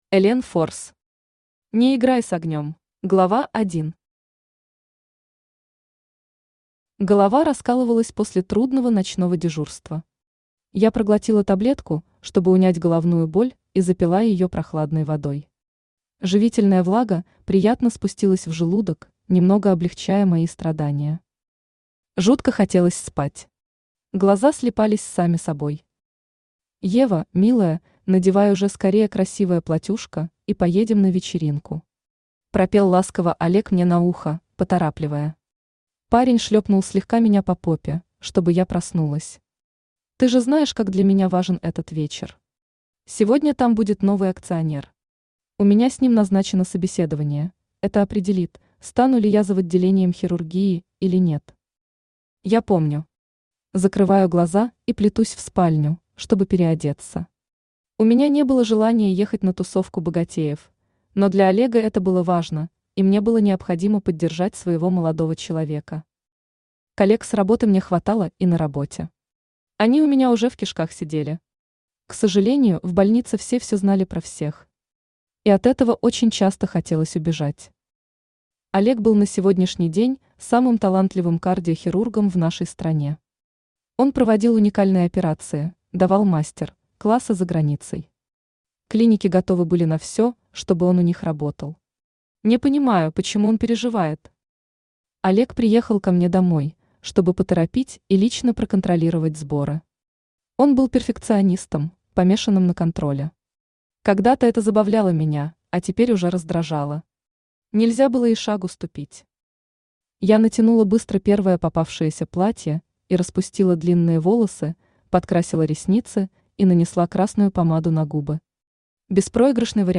Аудиокнига Не играй с огнём | Библиотека аудиокниг
Aудиокнига Не играй с огнём Автор Элен Форс Читает аудиокнигу Авточтец ЛитРес.